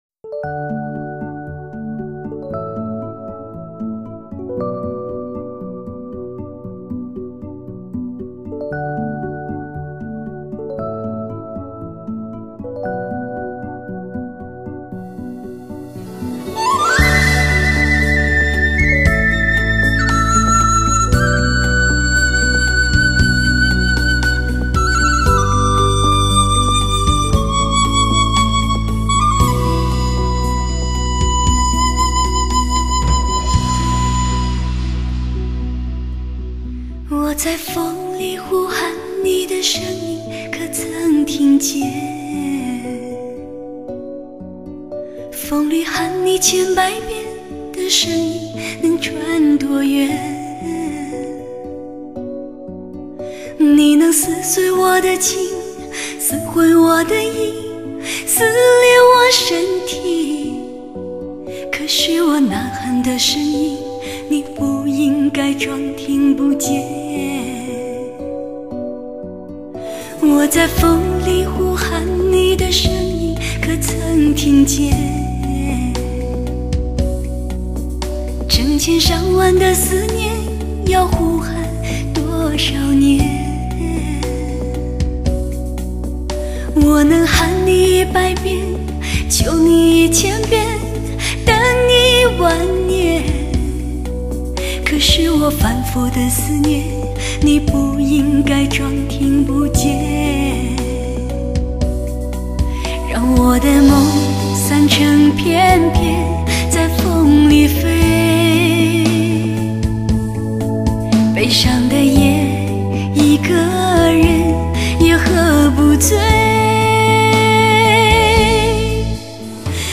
细腻和谐交融，歌声化作滴滴关怀，长驱直入心底。